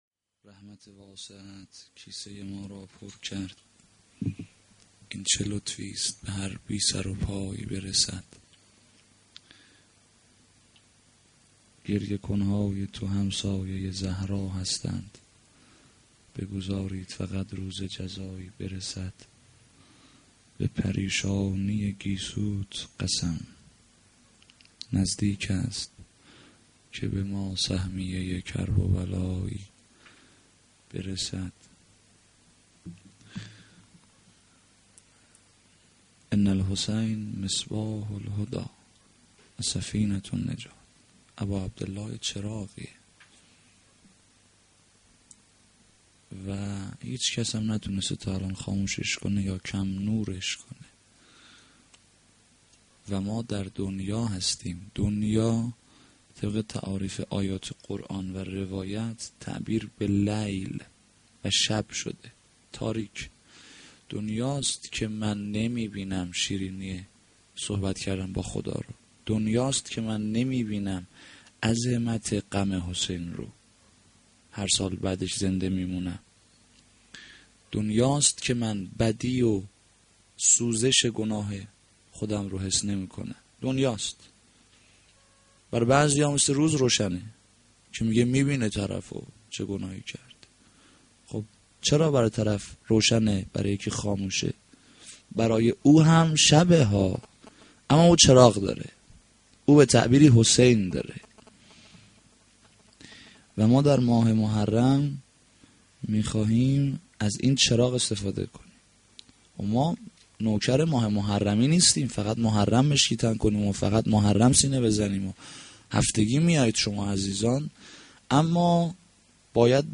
sh-1-moharram-92-sokhanrani.mp3